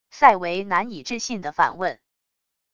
赛维难以置信的反问wav音频